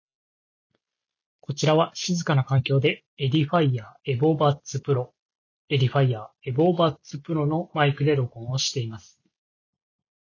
マイクの音質は以下のとおり。